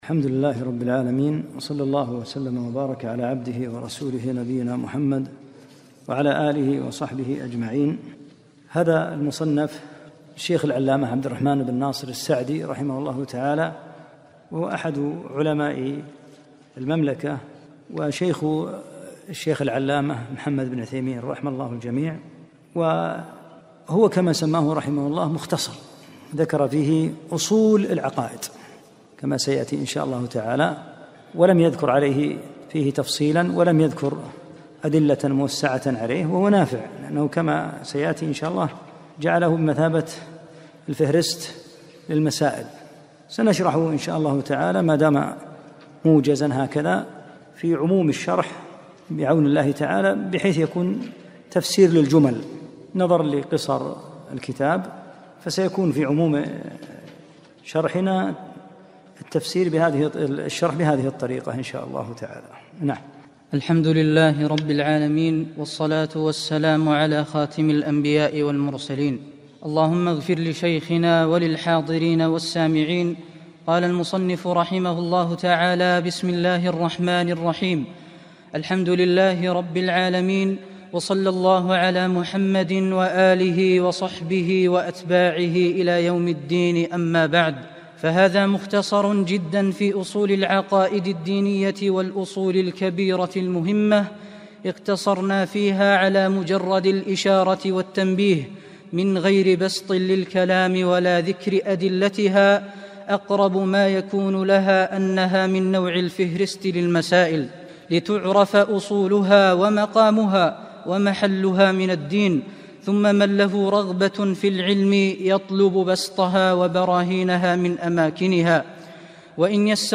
محاضرتان صوتيتان